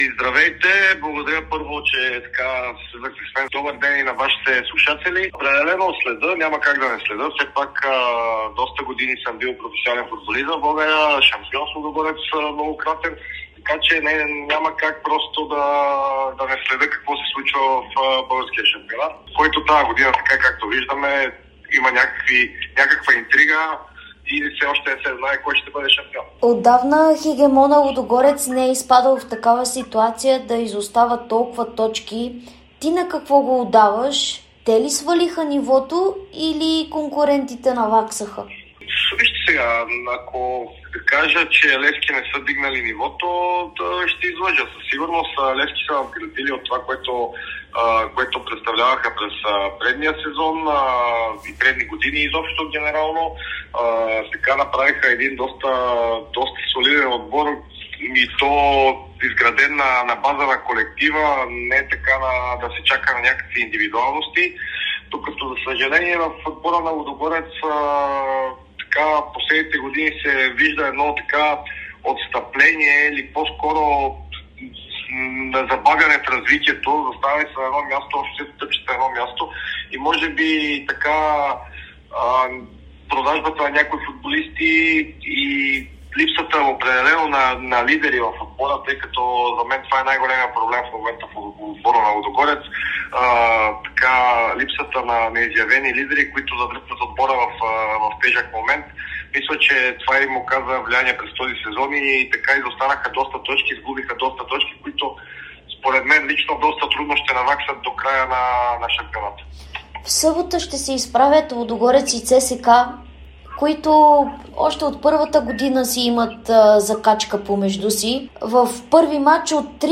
Бившият национален вратар - Владислав Стоянов, говори пред Дарик и Dsport за актуалната ситуация в българския шампионат, оценката му за отборите на Лудогорец и ЦСКА, предстоящия двубой помежду им, развитието на младите таланти на вратарския пост и ролята на треньорите за стабилизацията на отборите.